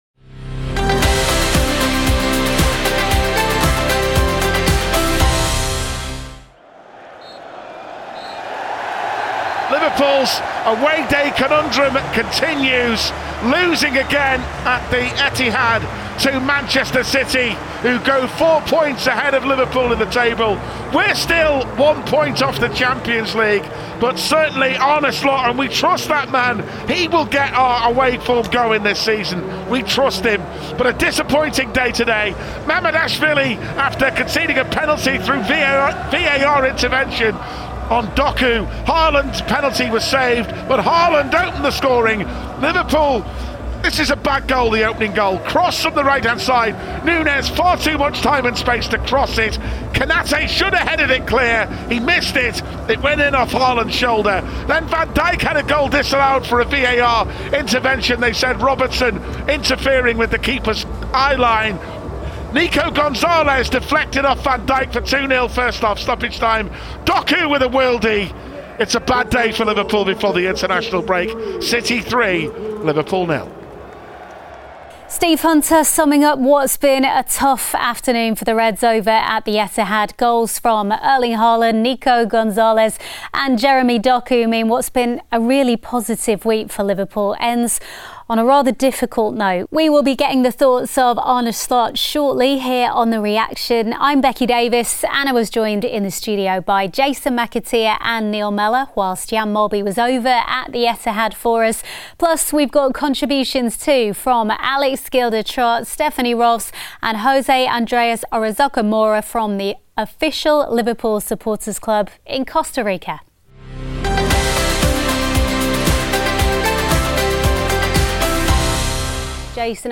In this episode of The Reaction, Arne Slot gives his assessment of events at the Etihad Stadium; we also bring you post-match analysis from former Reds Jason McAteer, Neil Mellor and Jan Molby.